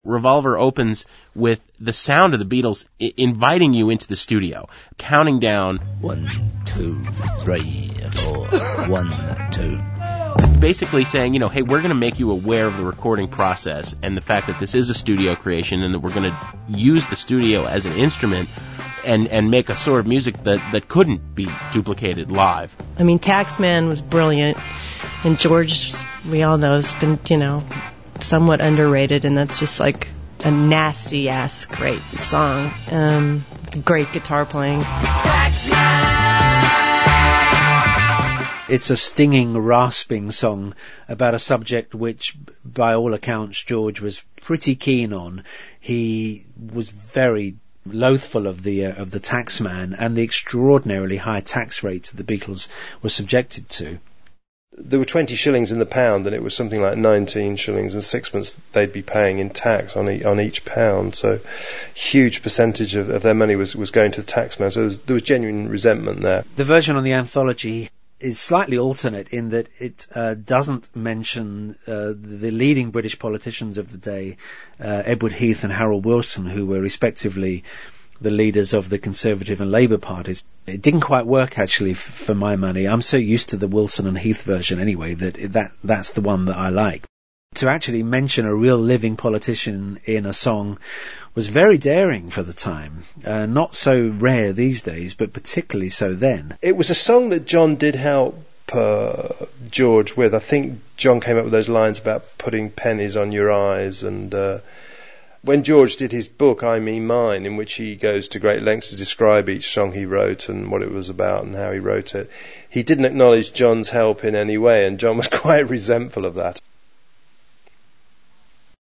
HEAR COMMENTS FROM OUR GUESTS ABOUT THE SONGS OF REVOLVER:
CommentsTaxman.mp3